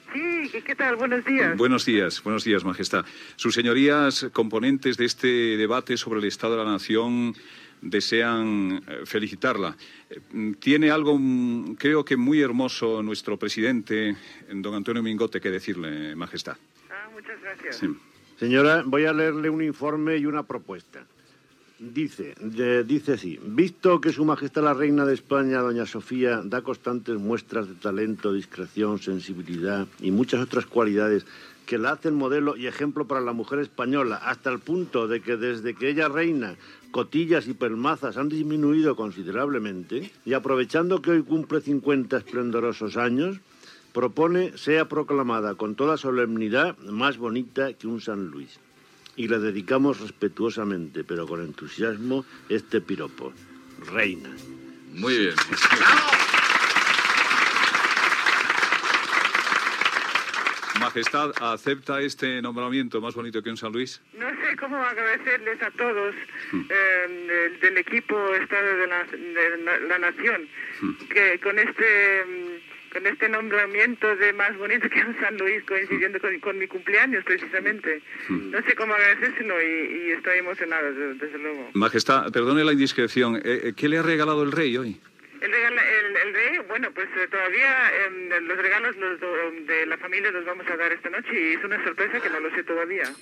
Trucada a la Reina Sofia amb motiu del seu 50è aniversari. Antonio Mingote de "El debate sobre el estado de la nación" llegeix el nomenament de "Más bonita que un San Luis".
Info-entreteniment